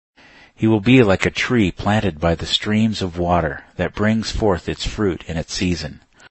reverse audio